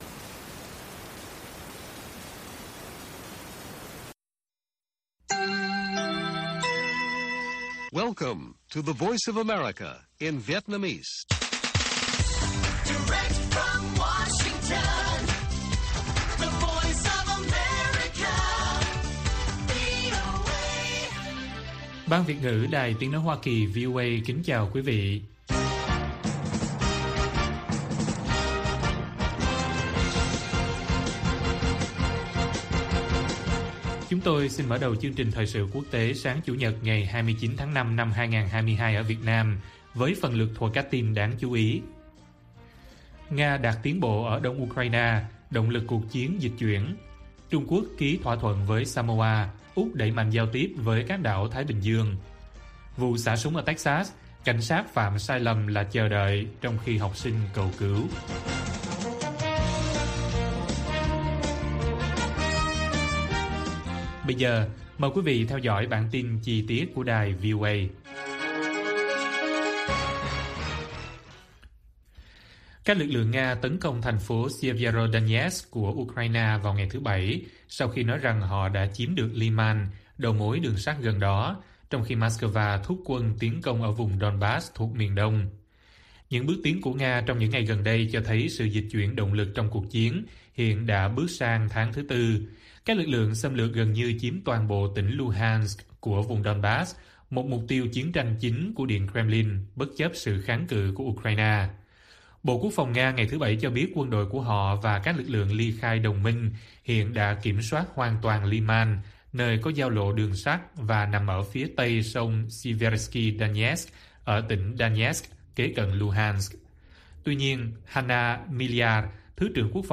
Nga đạt tiến bộ ở đông Ukraine, động lực cuộc chiến dịch chuyển - Bản tin VOA